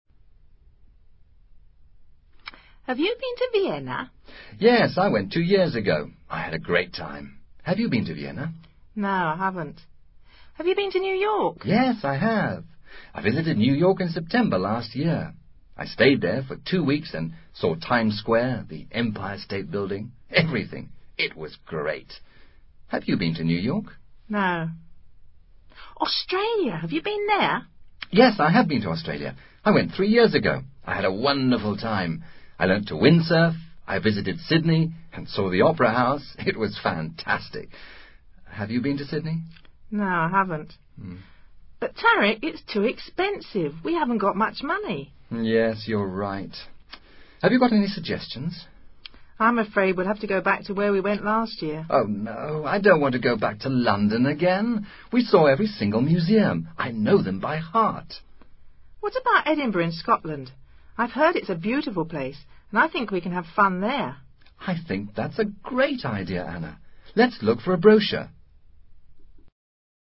Diálogo sobre viajes, adecuado para ejercitar el uso del Present perfect.